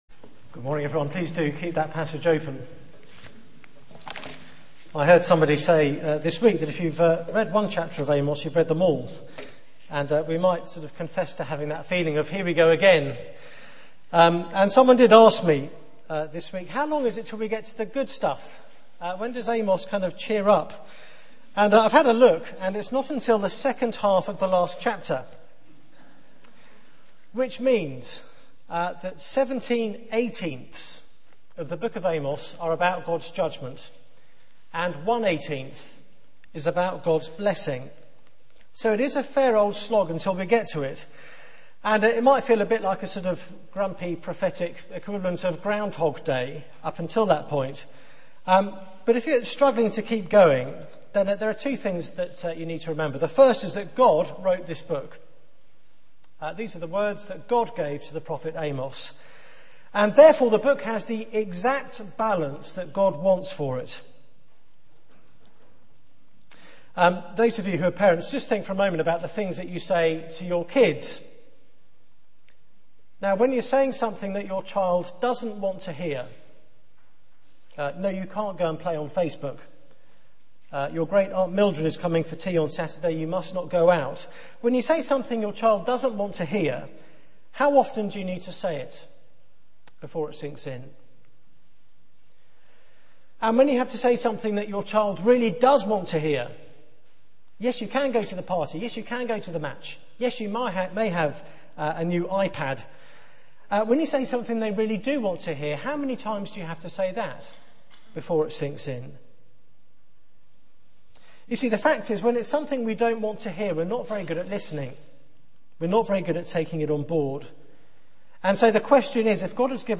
Media for 9:15am Service on Sun 31st Jan 2010 09:15 Speaker: Passage: Amos 6 Series: Amos Theme: The Fruit of Complacency There is private media available for this event, please log in. Sermon Search the media library There are recordings here going back several years.